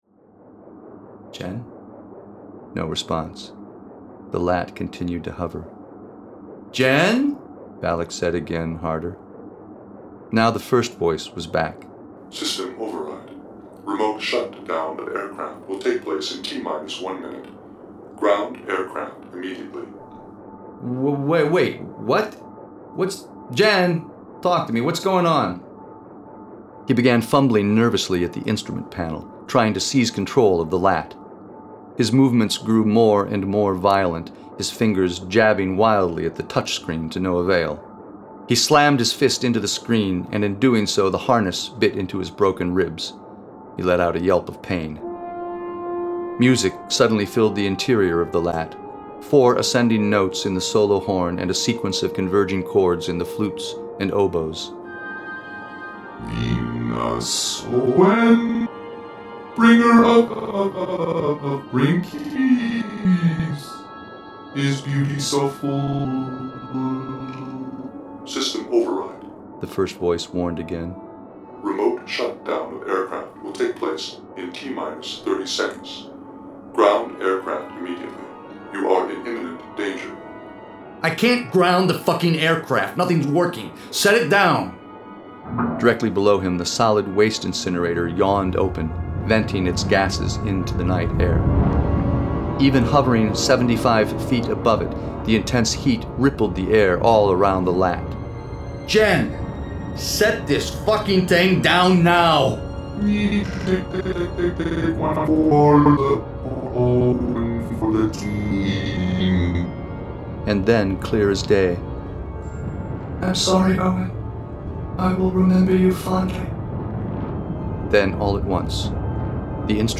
catharsis - An excerpt from the audiobook version